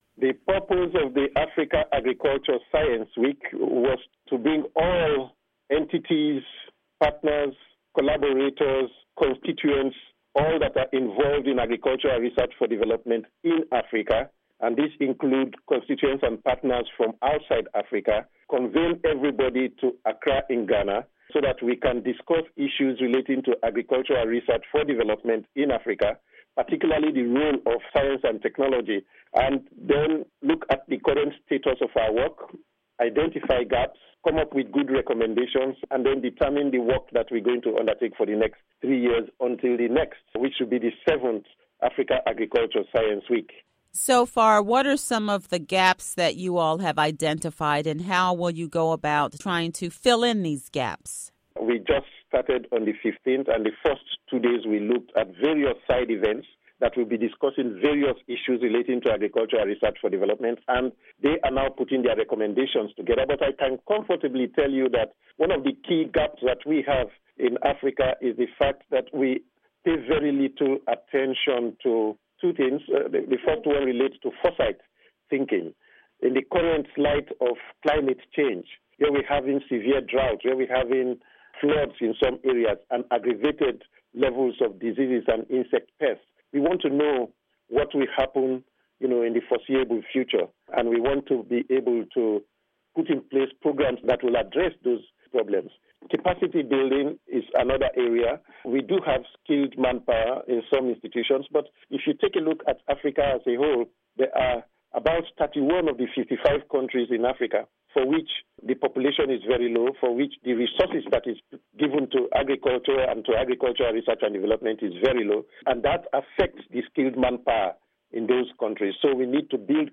Interview with Prof. Monty Jones